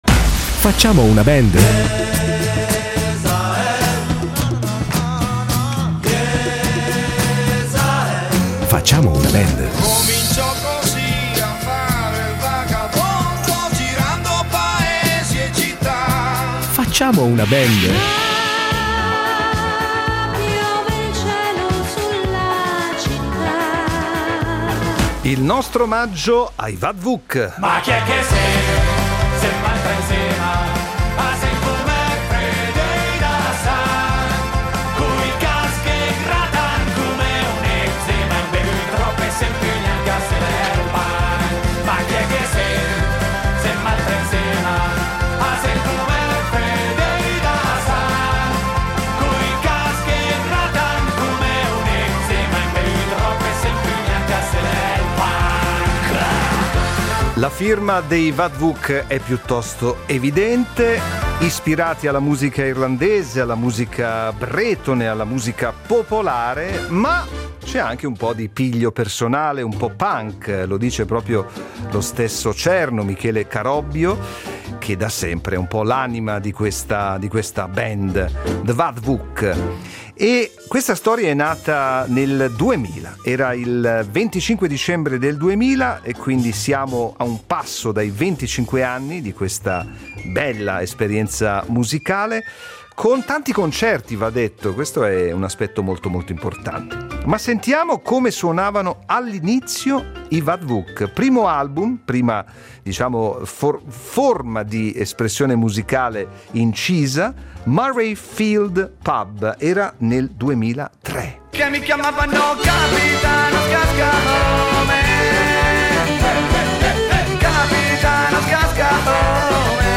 In ogni loro live non mancano mandolini, trombe, flauti, fisarmoniche, banjo ed entusiasmo. I Vad Vuc sono una delle band ticinesi più popolari, amatissime dal loro pubblico che li segue da più di 20 anni.